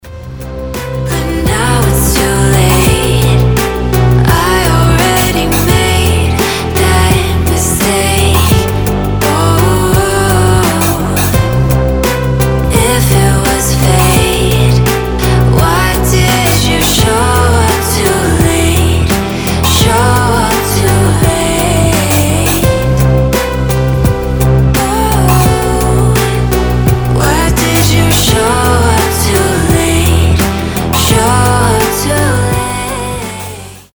• Качество: 320, Stereo
chillout
релакс
чувственные
медленные
расслабляющие
Чудный чилаут